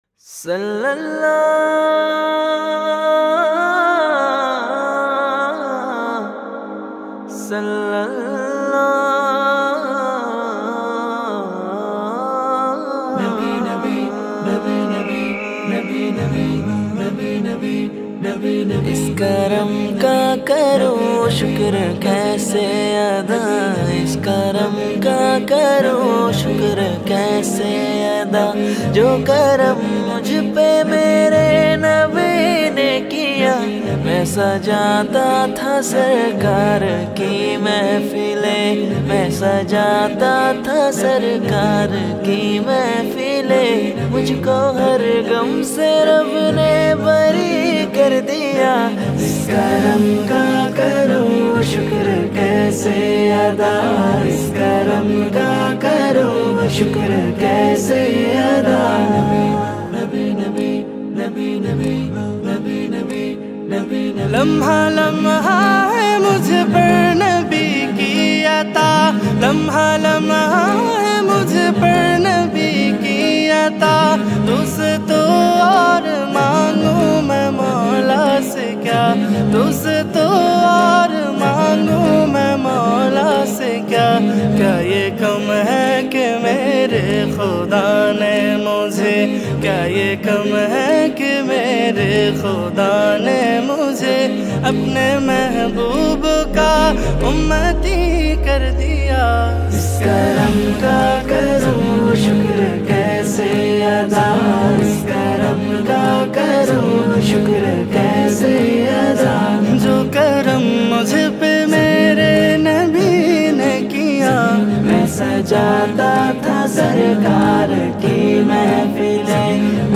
Naats